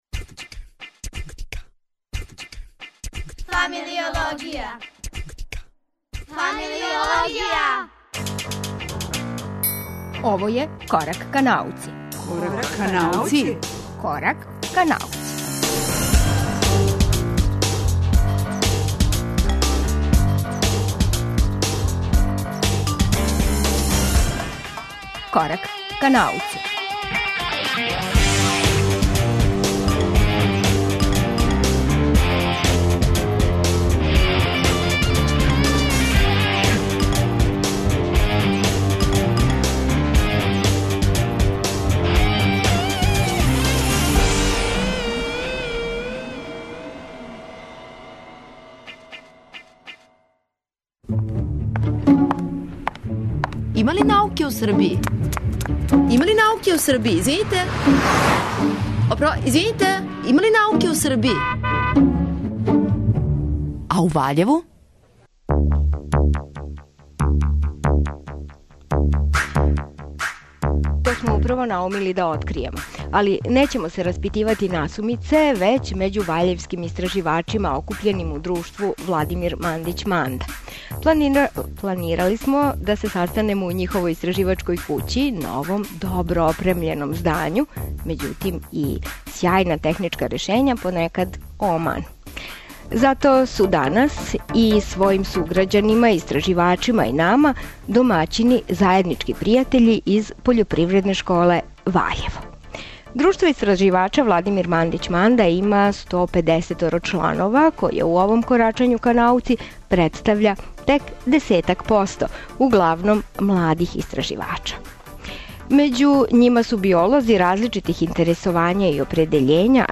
Емисија Корак ка науци је реализована из просторија Пољопривредне школе у Ваљеву.